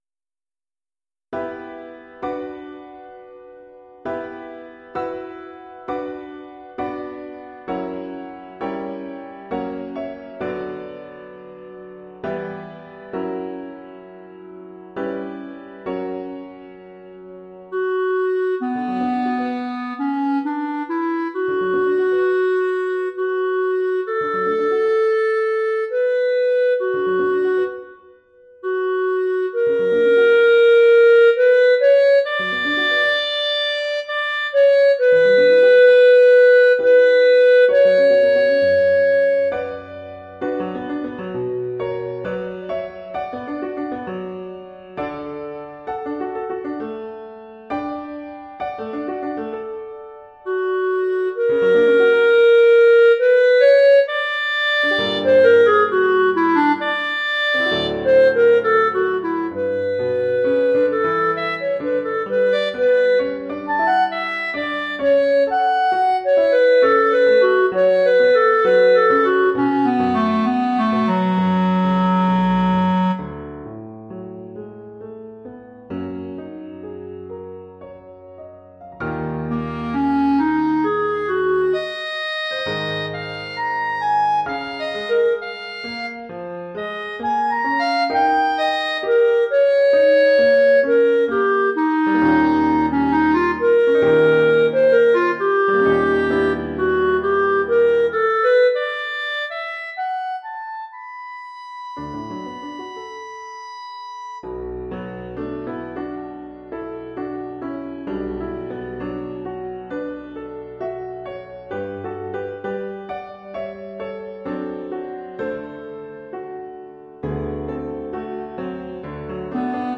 Formule instrumentale : Clarinette et piano
Oeuvre pour clarinette et piano.
Niveau : élémentaire.